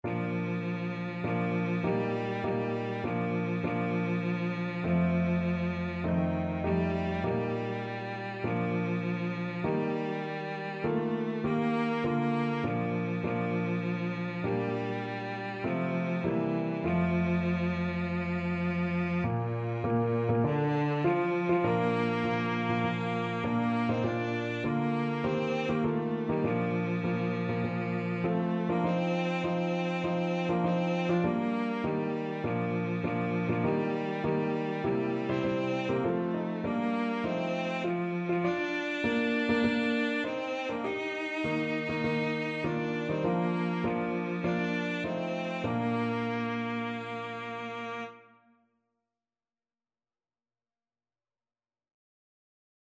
Christian
4/4 (View more 4/4 Music)
Classical (View more Classical Cello Music)